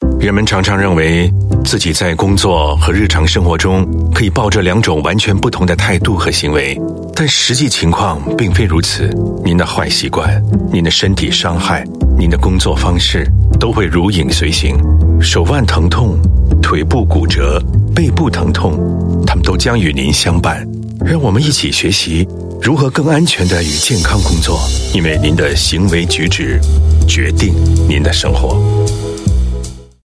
Voice Samples: Straight Read (Mandarin)
male